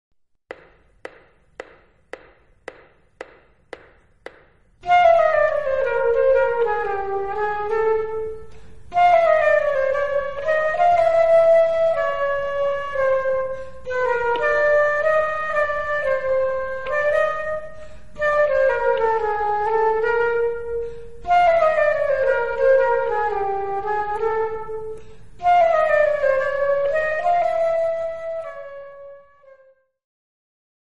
Instrumental Ensembles Flute
A challenge in close harmony playing.
Flute Duet